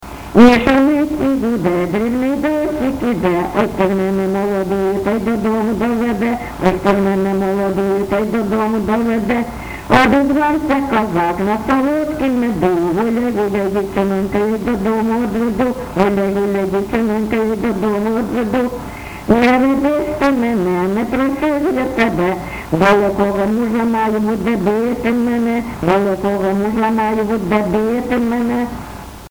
ЖанрПісні з особистого та родинного життя
Місце записус. Привілля, Словʼянський (Краматорський) район, Донецька обл., Україна, Слобожанщина